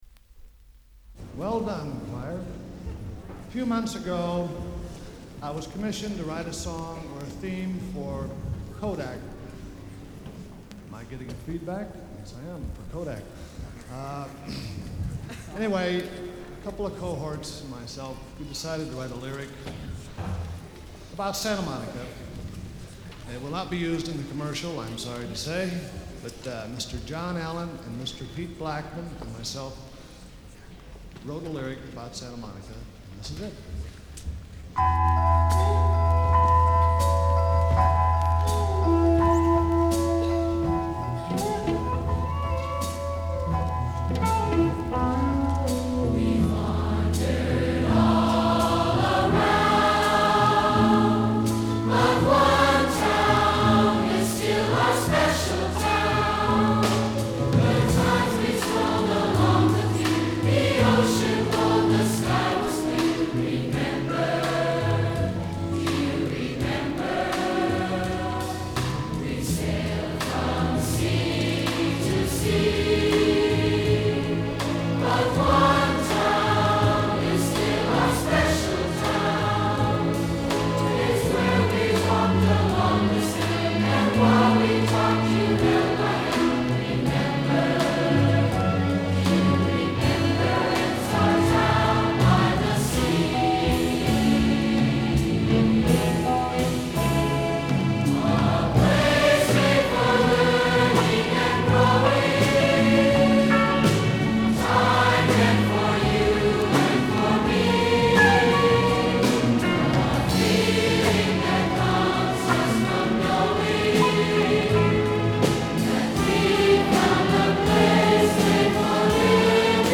I know I didn't dream them, because the song appeared on a recording from the 1975 edition of Santa Monica's annual Stairway of the Stars festivals (elementary, Junior High, and High School musicians performing in the Santa Monica Civic Auditorium, along with a guest celebrity).
(This video has since been removed, but another site sampled the same track from the vinyl LP, which is available